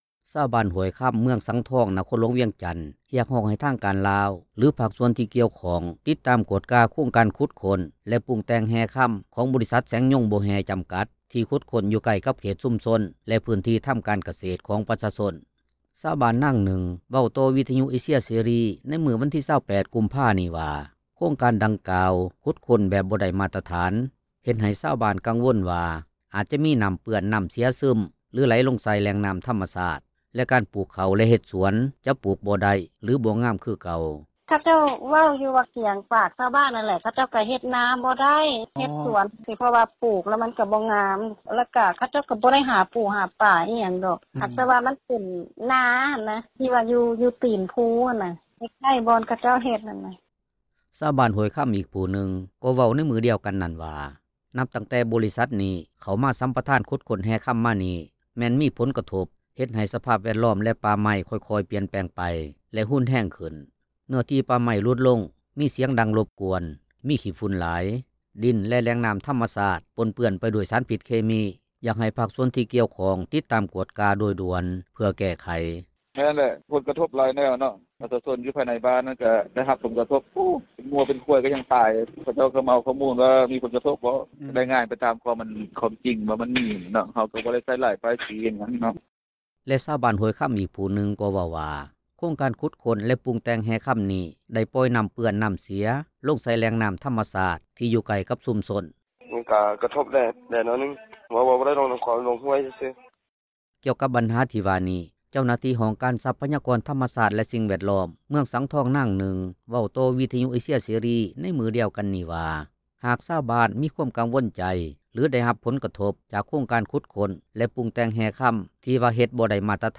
ຊາວບ້ານນາງນຶ່ງ ເວົ້າຕໍ່ວິທຍຸເອເຊັຍເສຣີ ໃນມື້ວັນທີ 28 ກຸມພານີ້ວ່າ ໂຄງ ການດັ່ງກ່າວ ຂຸດຄົ້ນແບບບໍ່ໄດ້ມາຕຖານ, ເຮັດໃຫ້ຊາວບ້ານກັງວົນວ່າ ອາດຈະມີນໍ້າເປື້ອນ, ນໍ້າເສັຍ ຊຶມ ແລະໄຫລລົງໃສ່ແຫລ່ງນໍ້າທໍາມະຊາດ ແລະການປູກເຂົ້າ ແລະເຮັດສວນ ຈະປູກບໍ່ໄດ້ ຫລືບໍ່ງາມຄືເກົ່າ.
ທ່ານ ຄໍາພັນ ພົມມະທັດ ປະທານອົງການກວດກາແຫ່ງຣັຖ ໄດ້ຣາຍງານຕໍ່ກອງປະຊຸມສໄມ ສາມັນເທື່ອທີ 6 ຂອງສະພາແຫ່ງຊາດ ຊຸດທີ 9 ໃນມື້ວັນທີ 7 ພຶສຈິກາ 2023 ຕອນນຶ່ງວ່າ: